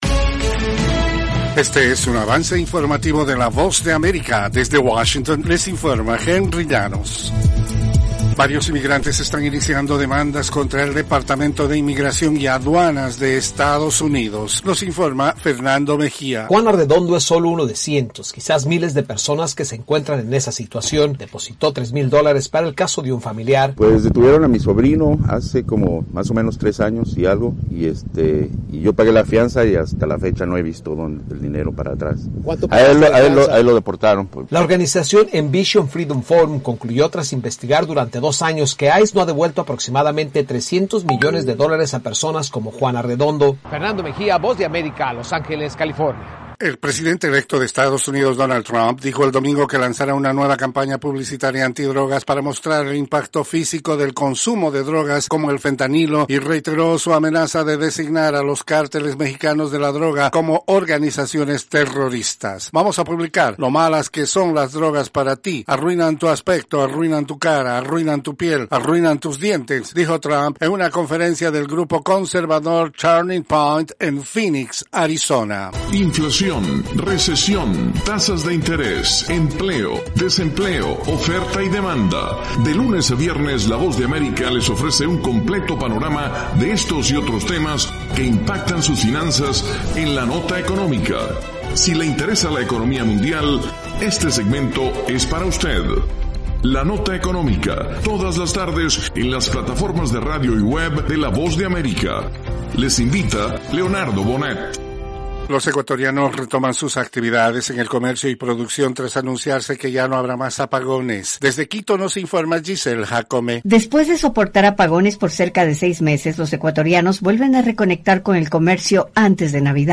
Cápsula informativa de tres minutos con el acontecer noticioso de Estados Unidos y el mundo. [1:00am Hora de Washington].